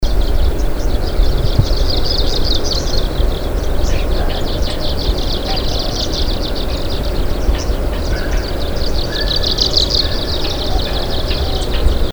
Pouillot boréal (Phylloscopus borealis )
Chant enregistrés le 04 mai 2012, en Chine, province du Fujian, sur la cote près de Changle.